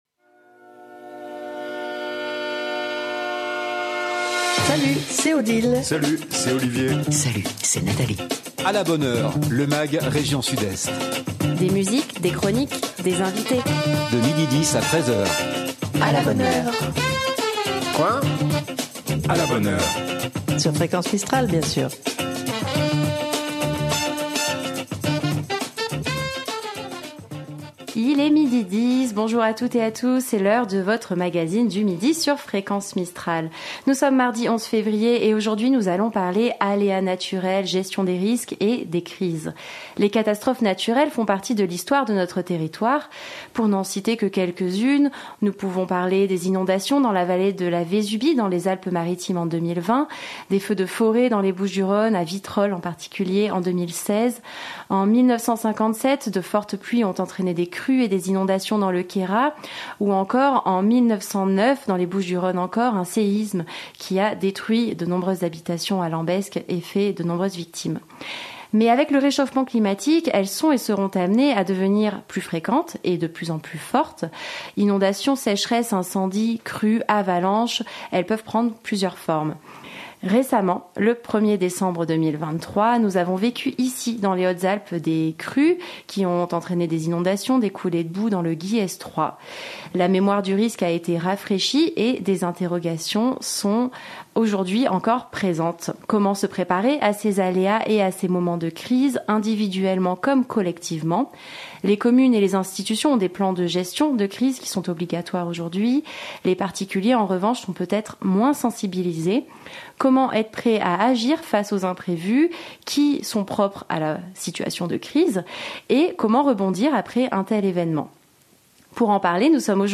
Du lundi au vendredi de 12h10 à 13h " À la bonne heure ! " Bienvenue dans le nouveau magazine région Sud-Est de Fréquence Mistral !